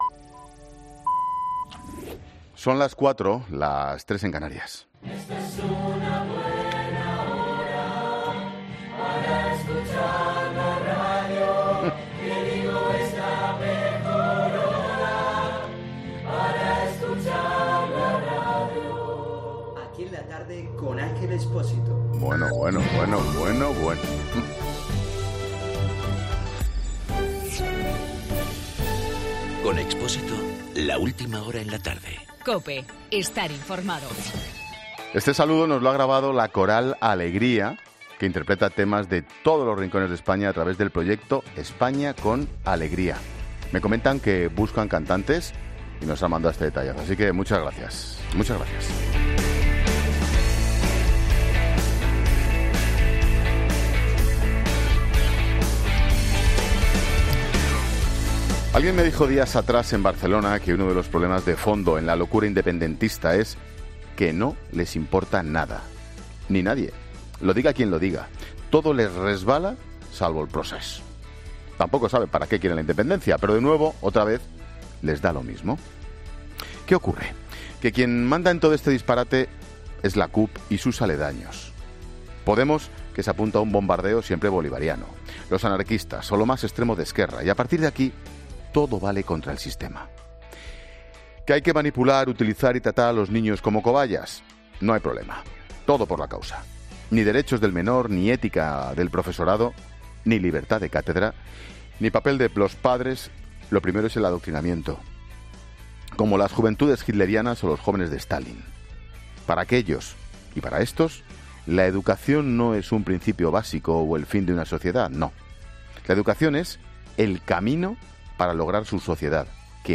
Monólogo de Expósito
Ángel Expósito analiza en su monólogo de las 16 horas la deriva secesionista del Govern.